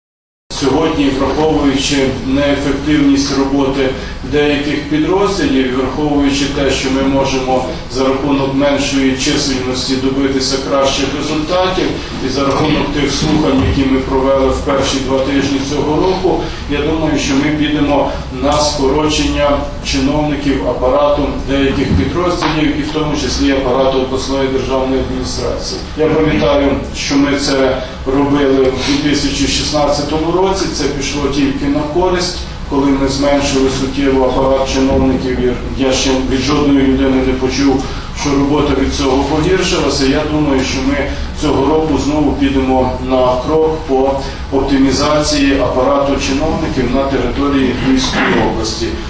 Враховуючи неефективність роботи деяких підрозділів, відбудеться скорочення чиновників апарату та департаментів Львівської ОДА. Про це сьогодні, 14 січня, повідомили під час апаратної наради.